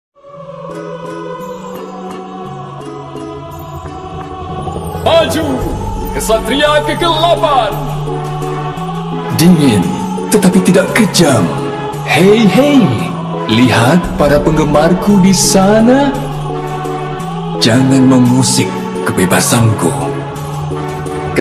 Kategori: Suara viral
Keterangan: Efek suara meme Dingin tetapi tidak kejam Mp3...